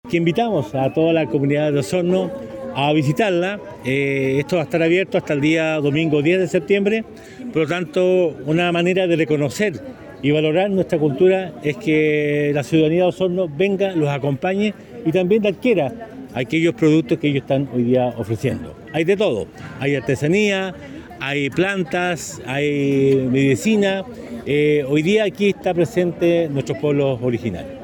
Del mismo modo, el jefe comunal hizo una invitación a la comunidad para acudir a la Feria Indígena y adquirir los diversos productos que se exponen en la instancia.